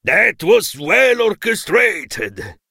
chuck_lead_vo_06.ogg